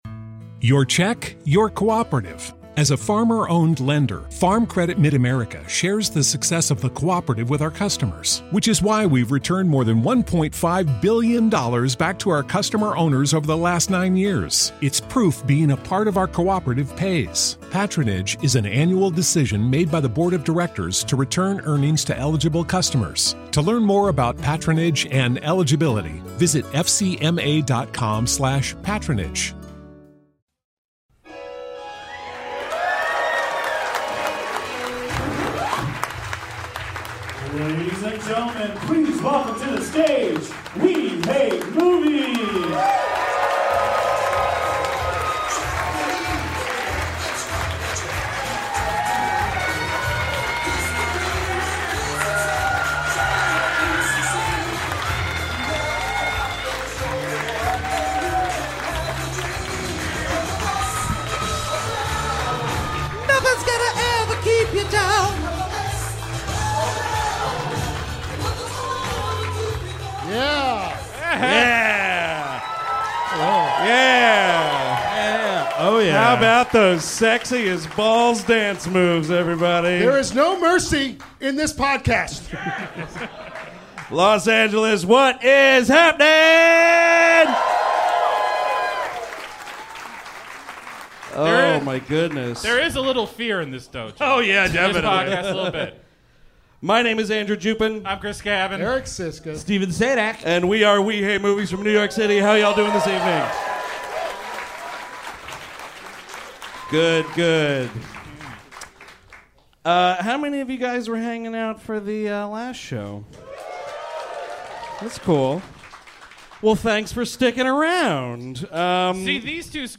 Episode 455 - The Karate Kid (Live in Hollywood)
On this week's episode, the gang is chatting about 1984's The Karate Kid LIVE from the Improv in Hollywood, California!